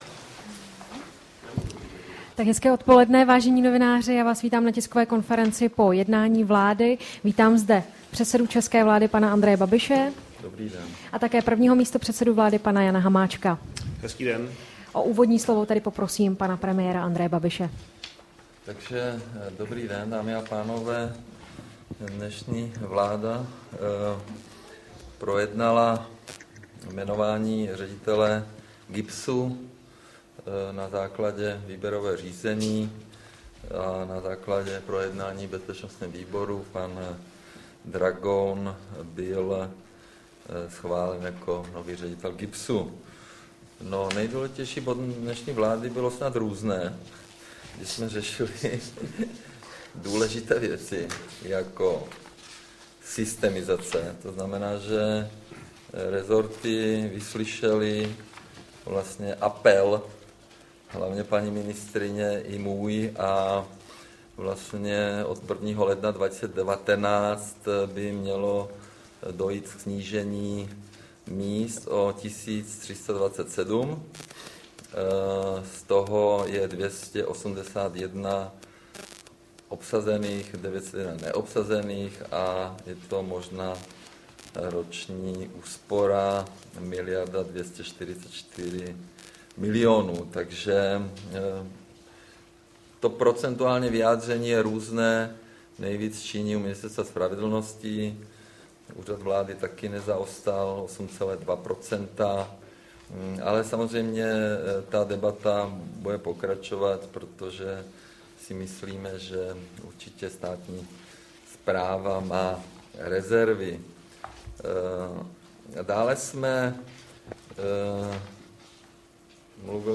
Tisková konference po jednání vlády, 29. srpna 2018